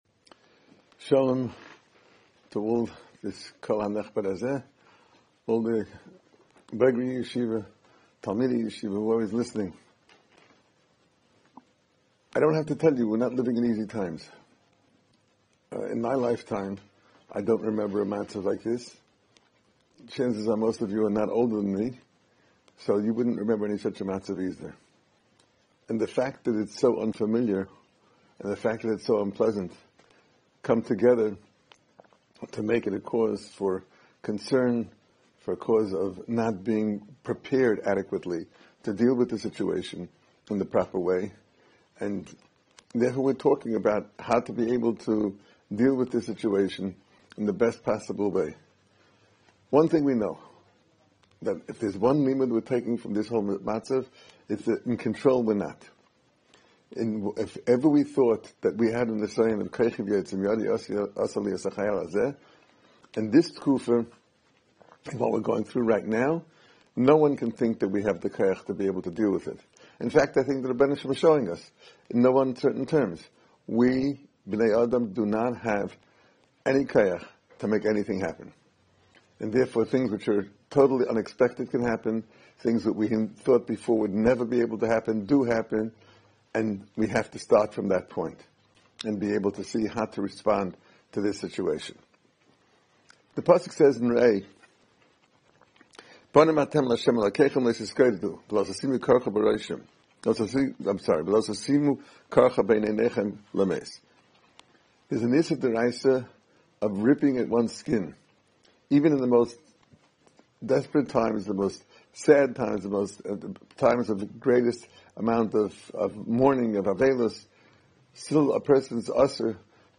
Special Lecture - Ner Israel Rabbinical College